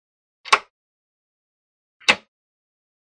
Звуки розетки, выключателей
Выключили и снова включили